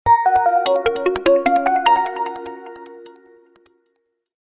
• Качество: 129, Stereo
короткие